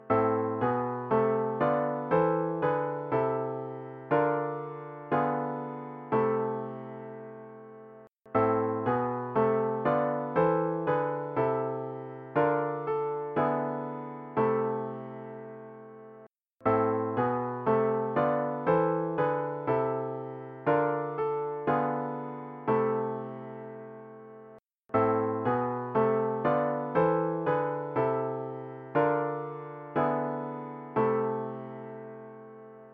2. 2c, 2b, 2a, 2d